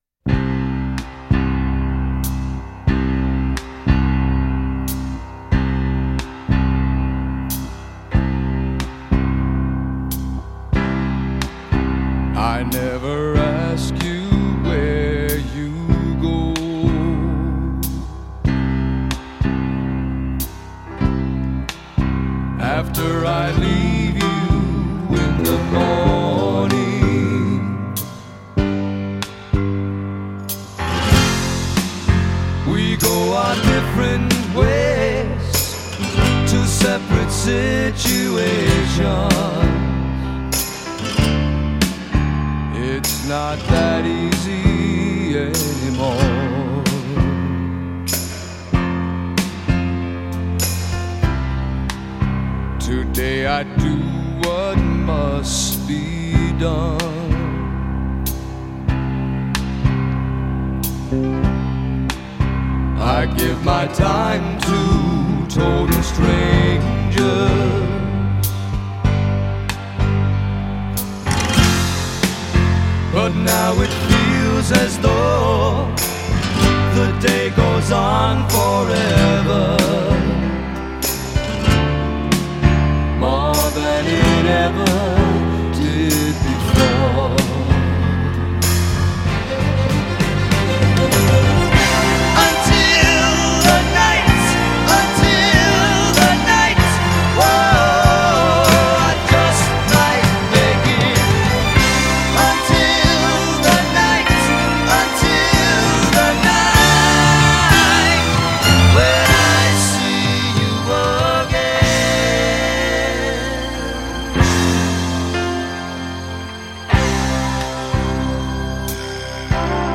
pure Righteous Brothers melodrama